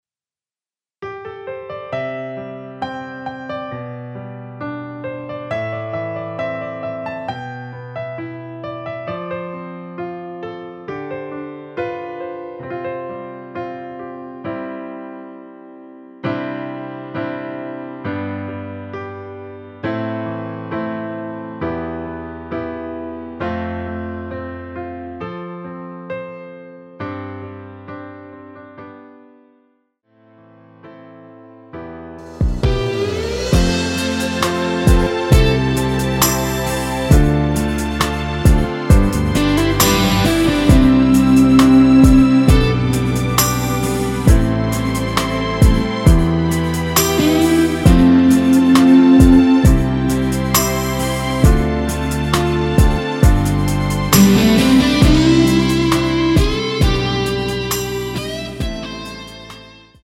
엔딩이 페이드 아웃이라 노래 하시기 좋게 엔딩을 만들어 놓았습니다.
음정은 반음정씩 변하게 되며 노래방도 마찬가지로 반음정씩 변하게 됩니다.
앞부분30초, 뒷부분30초씩 편집해서 올려 드리고 있습니다.